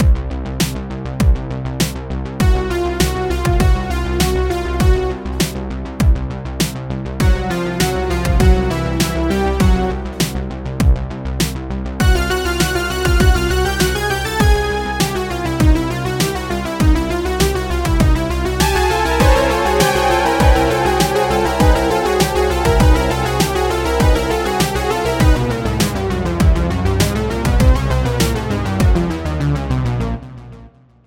Category: Instrumental Ringtones